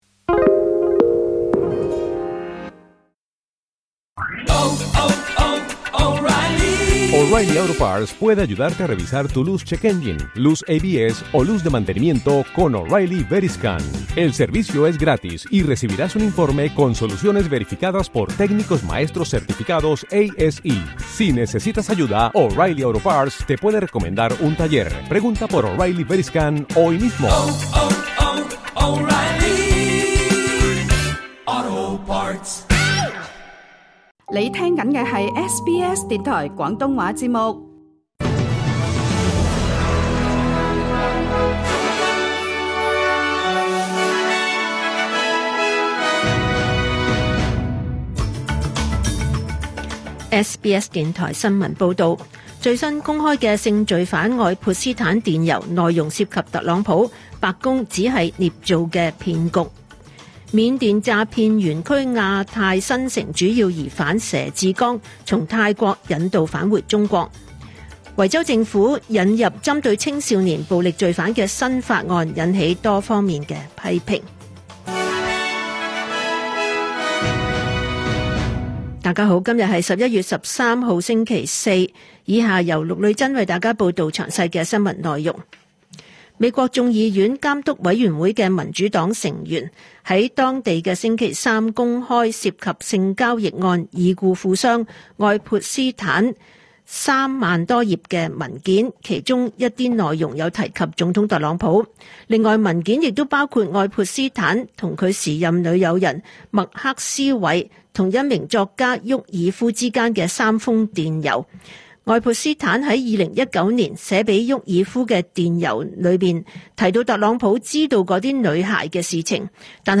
2025 年 11 月 13 日 SBS 廣東話節目詳盡早晨新聞報道。